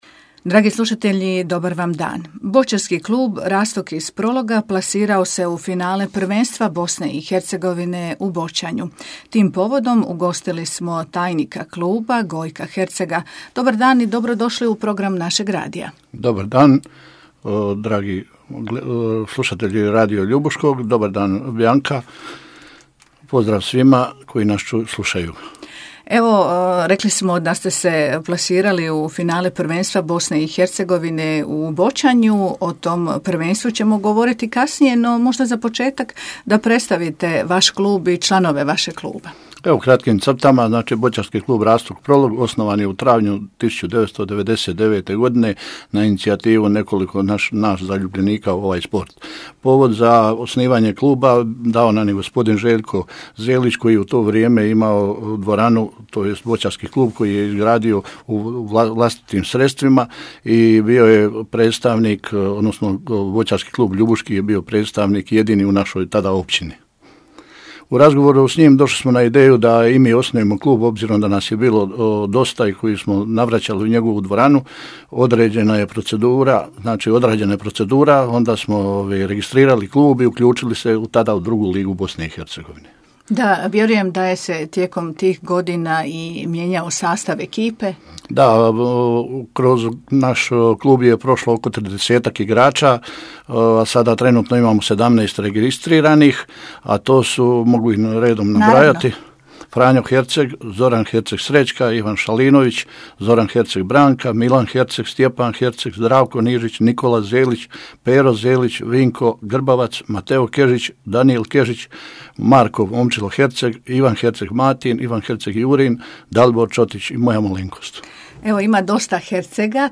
Finalni susret na rasporedu je u nedjelju 20. lipnja u Neumu. Tim povodom u programu Radija Ljubuški ugostili smo